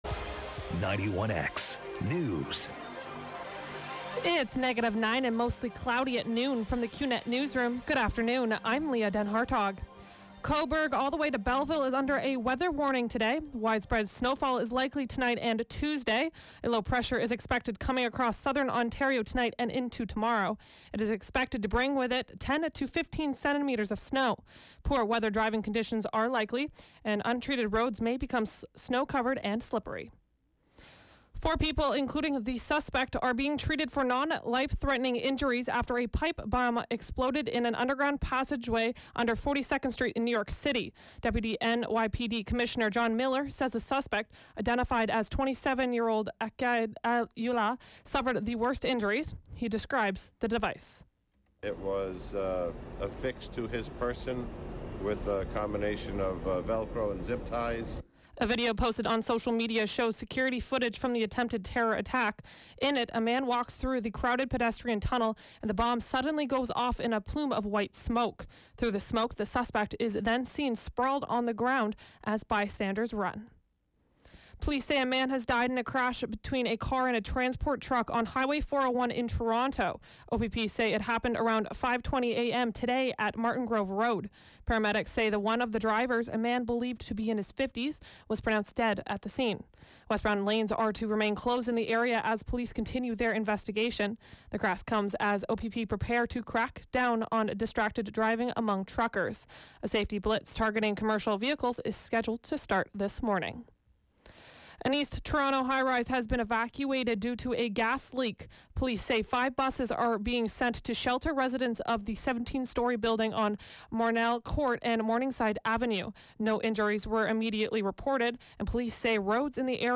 91X Newscast: Monday, Dec. 11, 2017, 12 p.m.